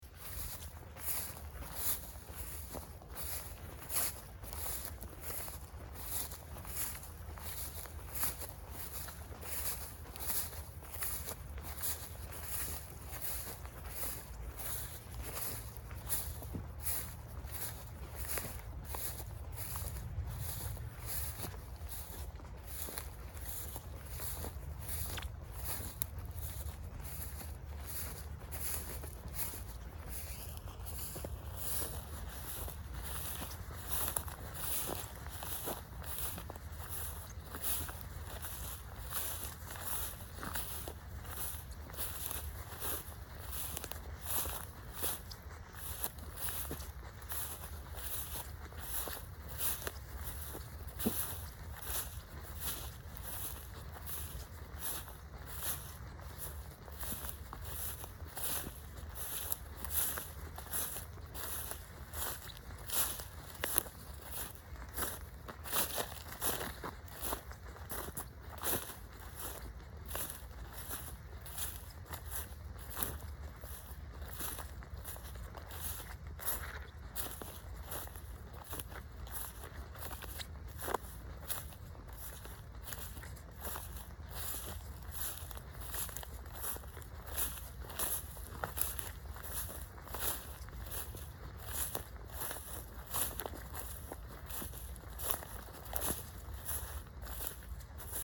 Звуки шагов по траве
В подборке представлены реалистичные записи ходьбы по сухой и влажной траве, утренней росе.
Человек идет по шуршащей траве